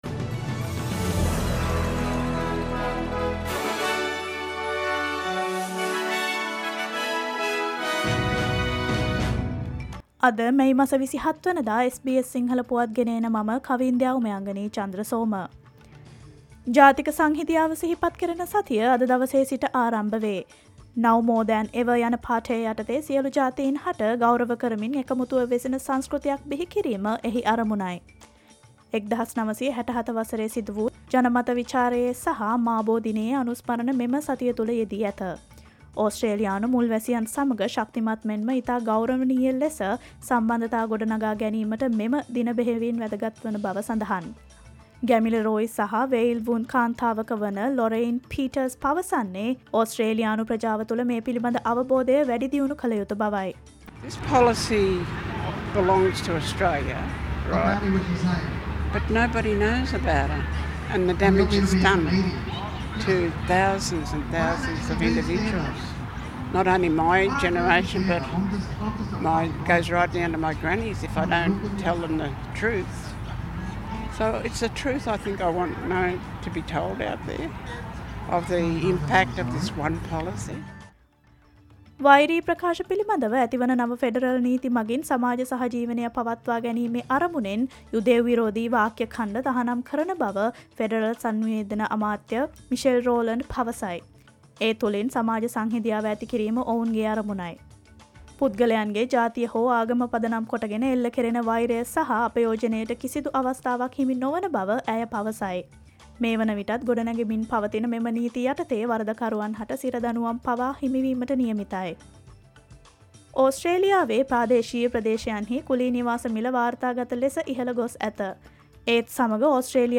Australia news in English, foreign and sports news in brief. Listen, SBS Sinhala News Flash today